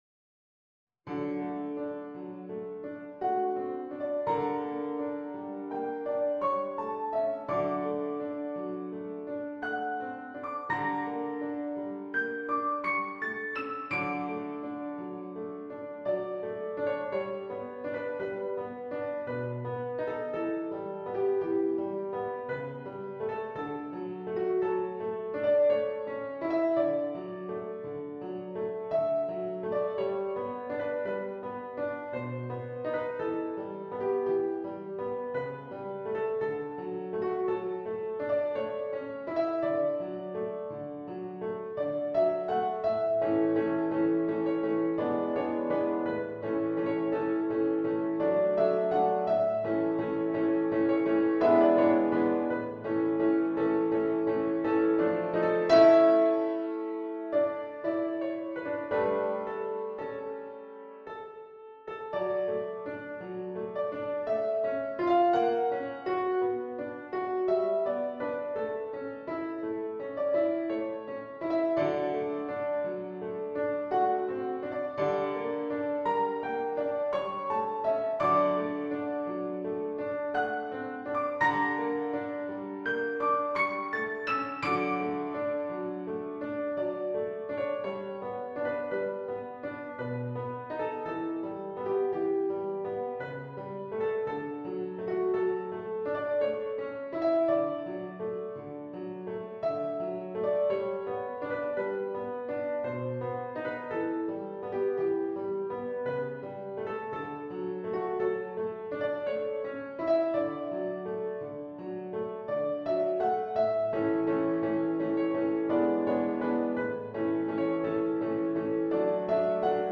Piano version
No parts available for this pieces as it is for solo piano.
3/4 (View more 3/4 Music)
~ = 56 Ziemlich langsam
Piano  (View more Intermediate Piano Music)
Classical (View more Classical Piano Music)